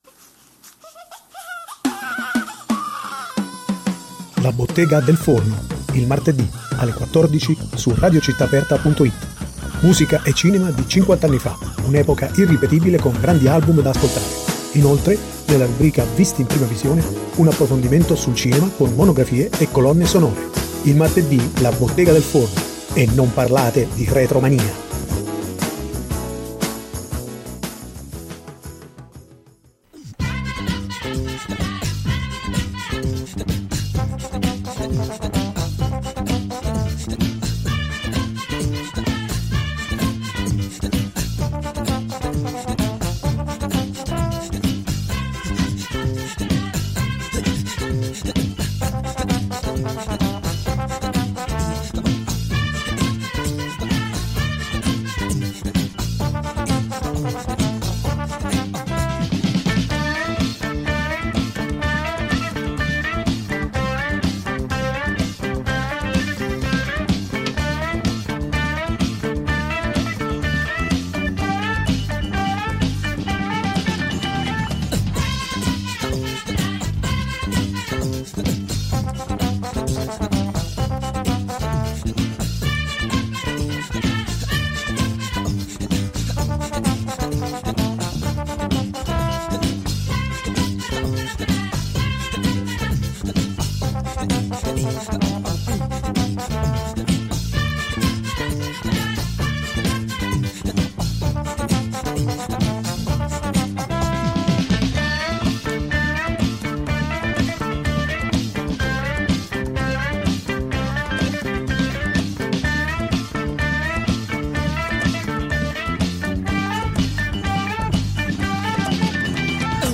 La rubrica è stata arricchita dalla testimonianza diretta del M° Franco Bixio, co-autore, insieme a Fabio Frizzi e Vince Tempera dell’indimenticabile tema dei titoli di testa e di coda, che ha contribuito in maniera decisiva al successo di questa pellicola.